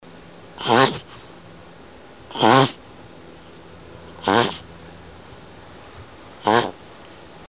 Черепаха издает звук